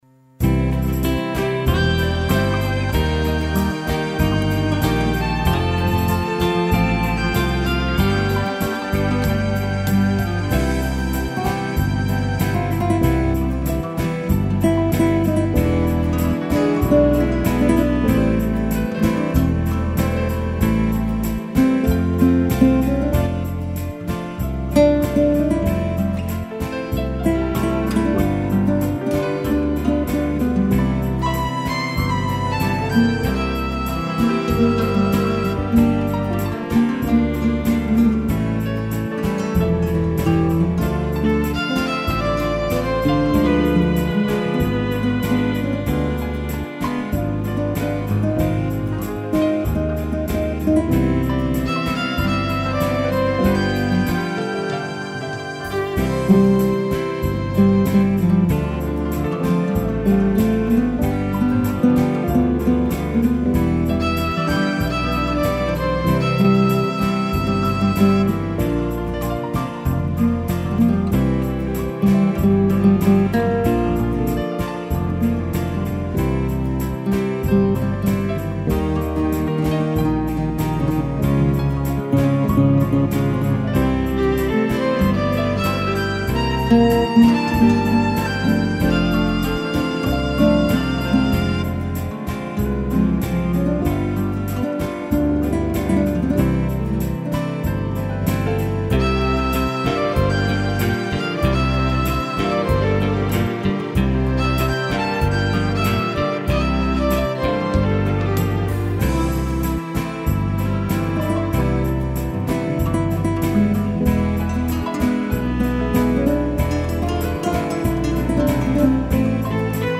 piano, violino e cello